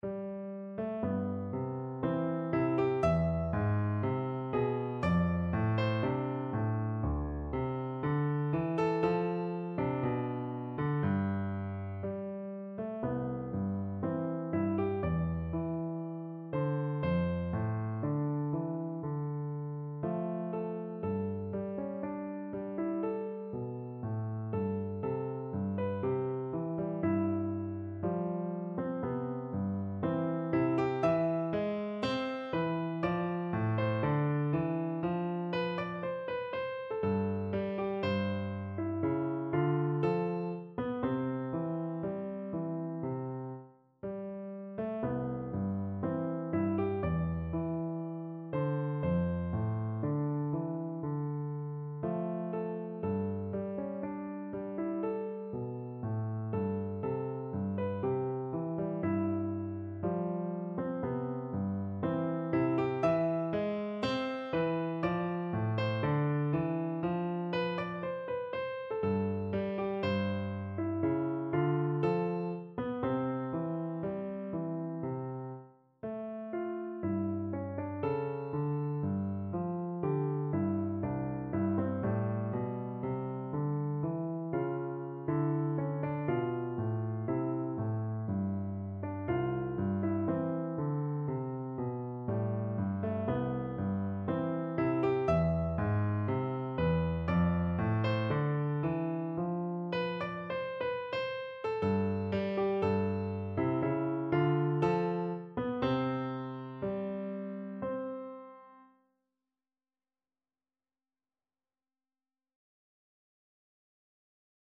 No parts available for this pieces as it is for solo piano.
C major (Sounding Pitch) (View more C major Music for Piano )
2/4 (View more 2/4 Music)
Andante =60
Piano  (View more Easy Piano Music)
Classical (View more Classical Piano Music)